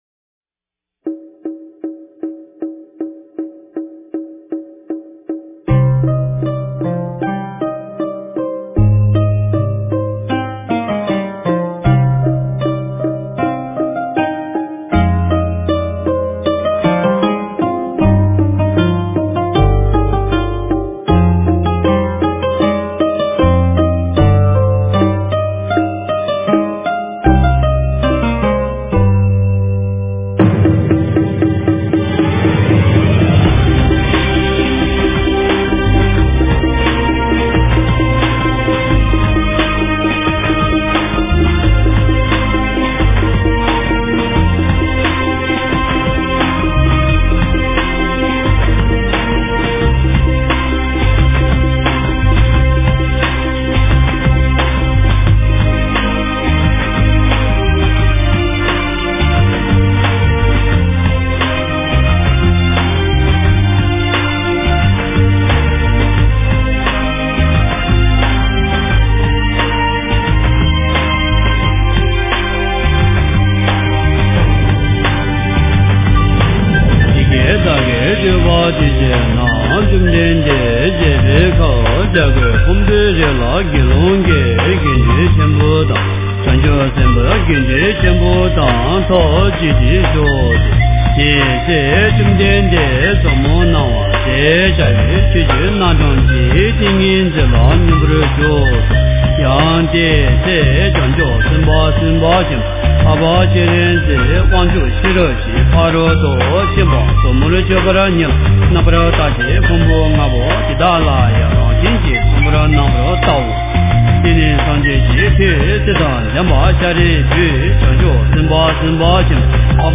心经-藏音读诵
诵经